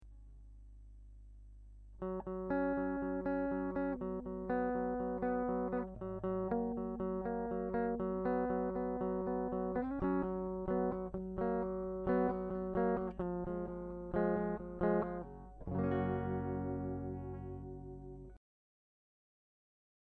Guitar Cable